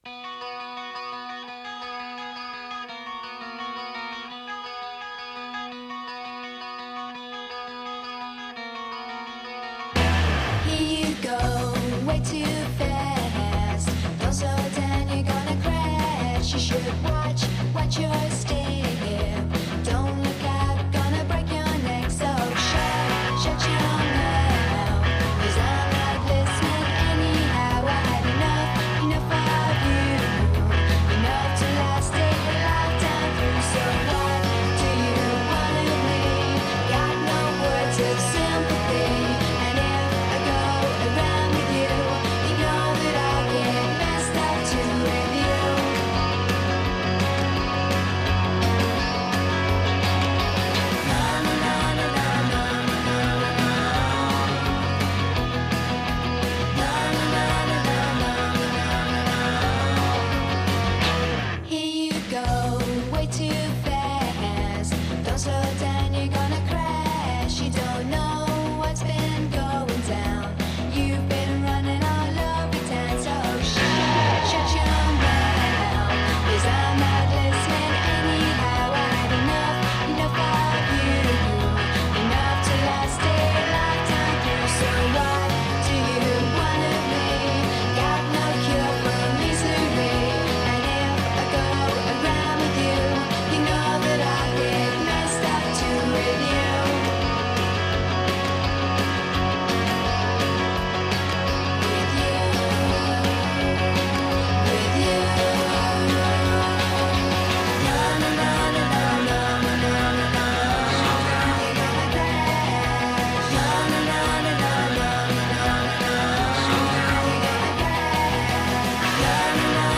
Indie Rock, Pop